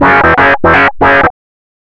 RIFFSYNT02-R.wav